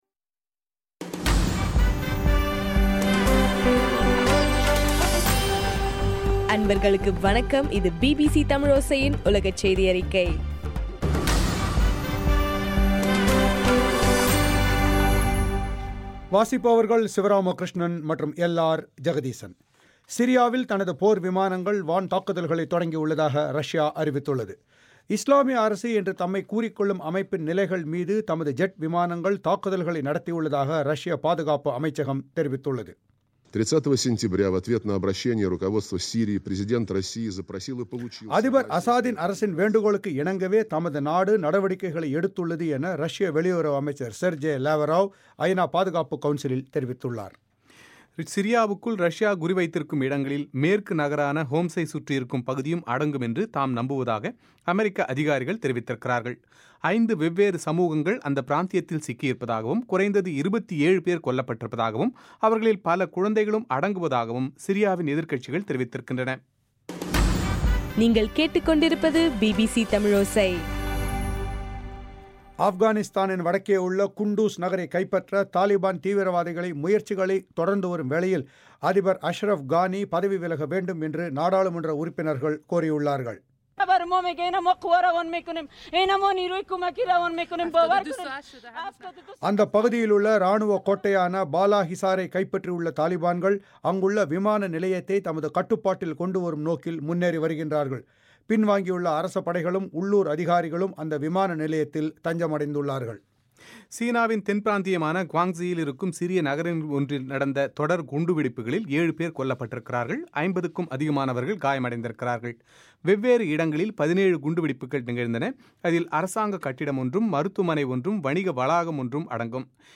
செப்டம்பர் 30 பிபிசியின் உலகச் செய்திகள்